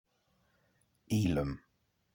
Elham (/ˈləm/